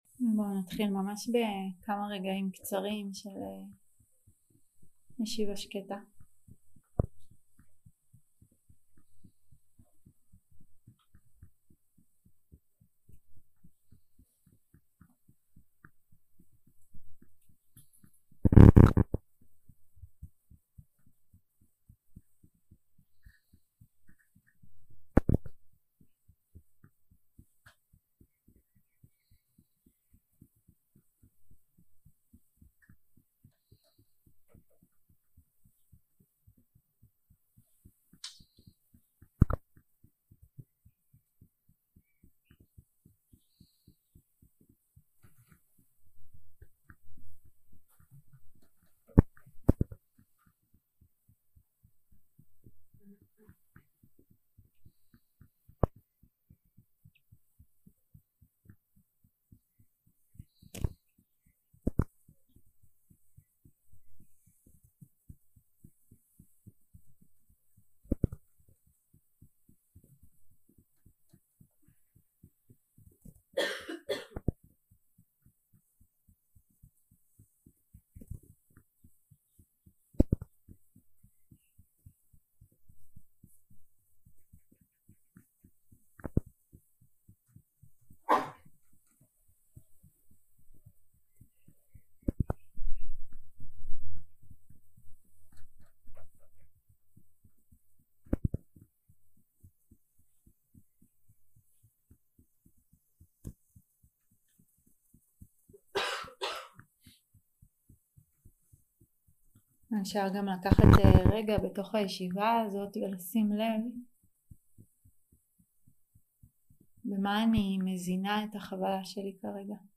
יום 2 – הקלטה 3 – צהריים – שיחת דהארמה – מהבנייה של דוקהה להתהוות של חופש 0:00 0:00 סוג ההקלטה: Dharma type: Dharma Talks שפת ההקלטה: Dharma talk language: Hebrew